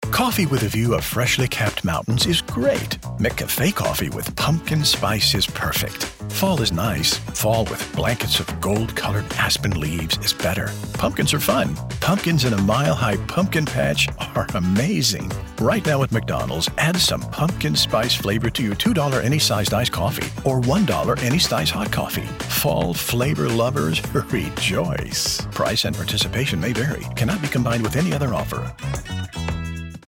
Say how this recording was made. Digital Home Studio Shure KSM 32 Large diaphragm microphone